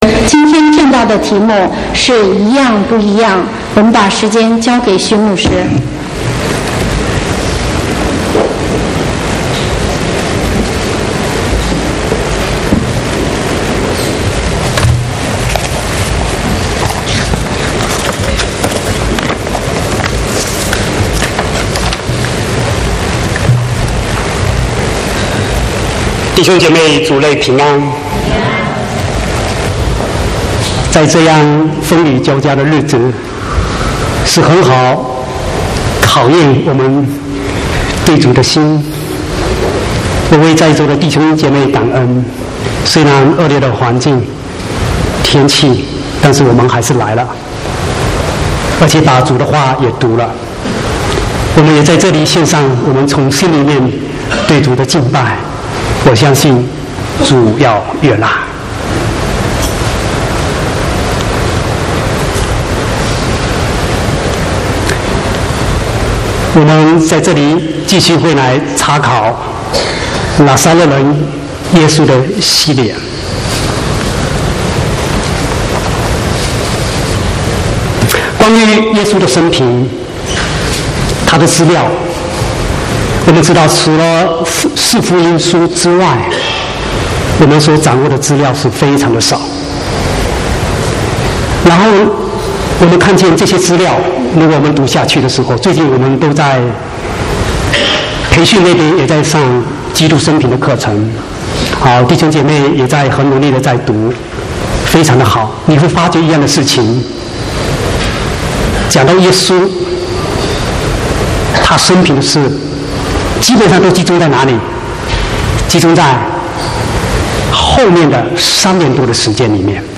5/6/2016國語堂講道